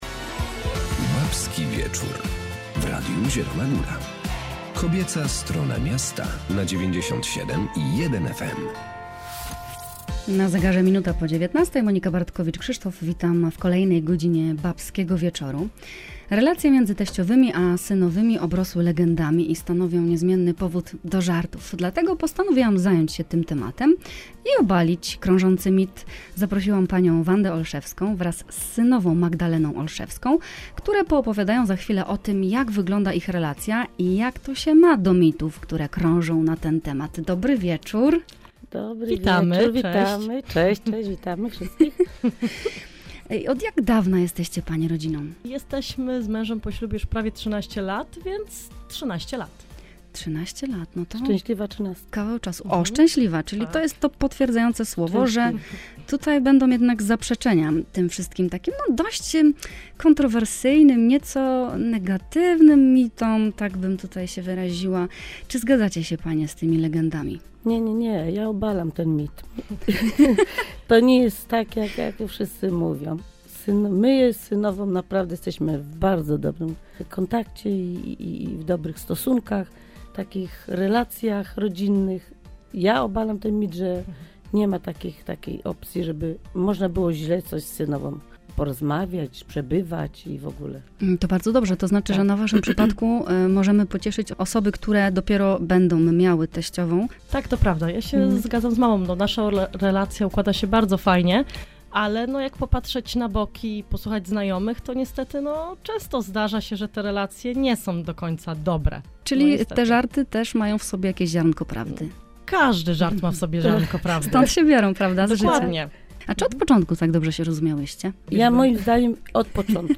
Relacje między teściowymi a synowymi obrosły legendami i stanowią niezmienny powód do żartów. Zaprosiłam do studia teściową i synową.